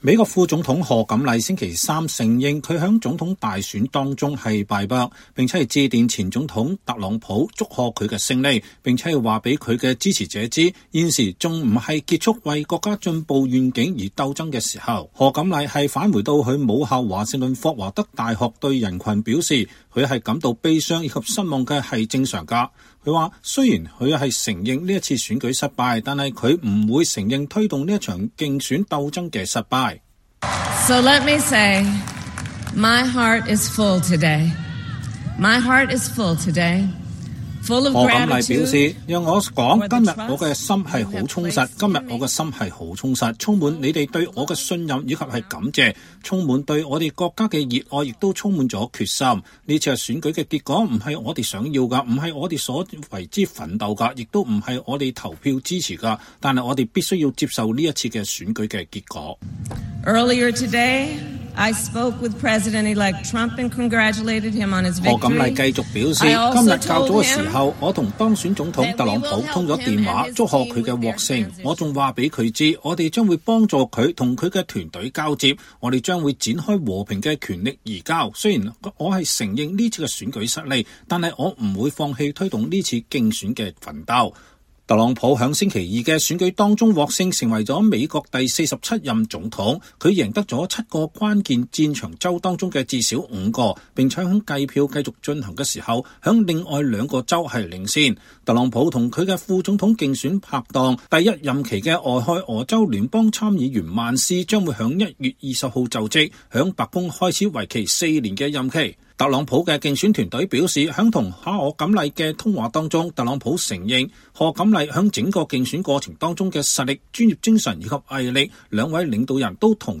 2024年11月6日，副總統賀錦麗在華盛頓霍華德大學校園發表2024年總統大選承認敗選的演講。